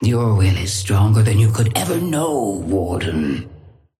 Sapphire Flame voice line - Your will is stronger than you could ever know, Warden.
Patron_female_ally_warden_start_04.mp3